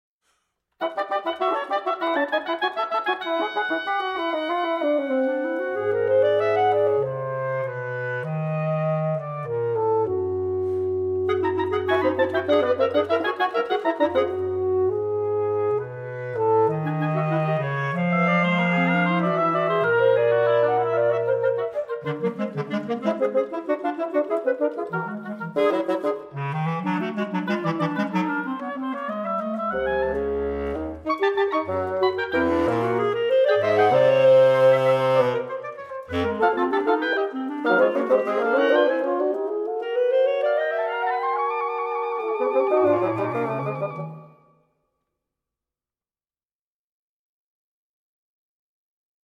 Reed Quintet